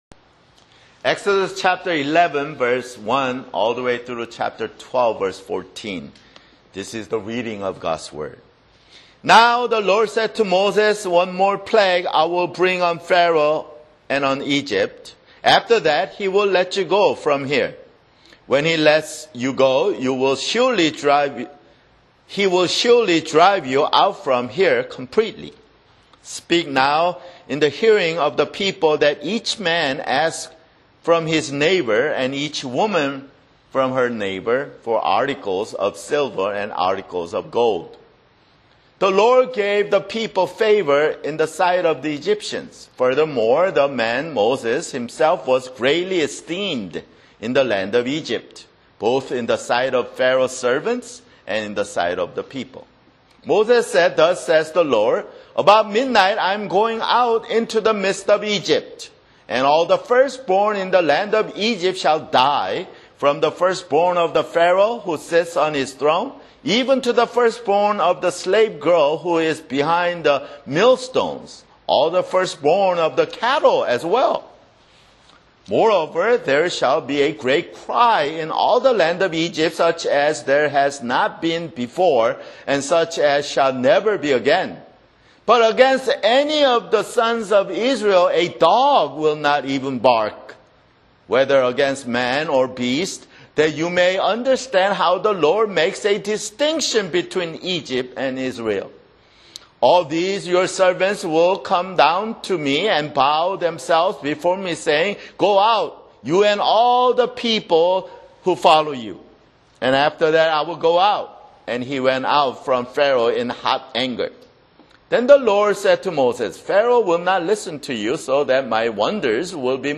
[Sermon] Exodus (24)